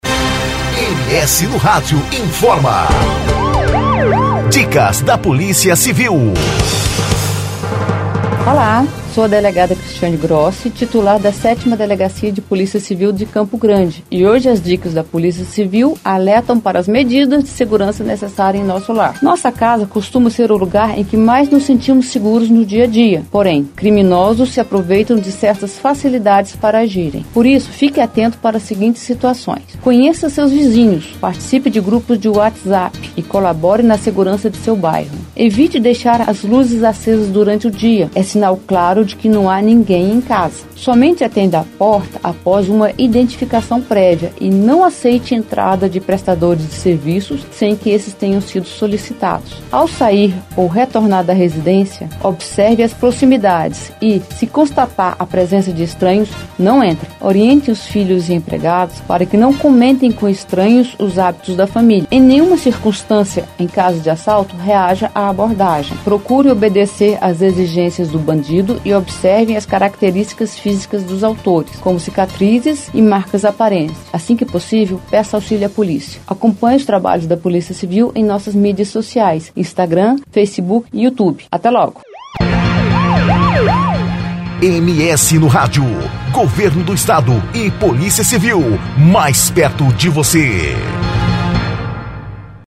Ela explica que apesar da residência costumar ser o lugar onde mais nos sentimos seguros, criminosos se aproveitam de certas facilidades para agirem. Por isso é importante ficar atento e seguir algumas dessas dicas.